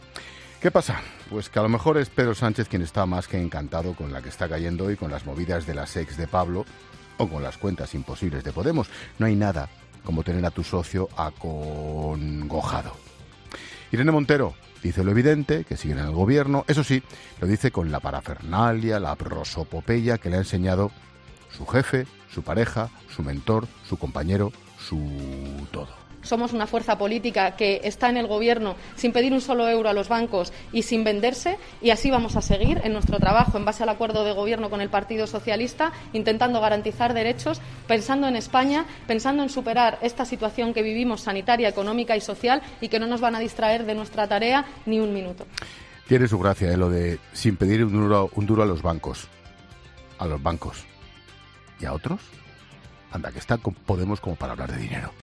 Esta tarde, el presentador de 'La Linterna', Ángel Expósito, ha dedicado parte de su monólogo de las 19h ha analizar la situación por la que atraviesa Podemos.